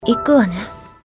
match-start.mp3